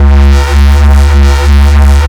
Desecrated bass hit 09.wav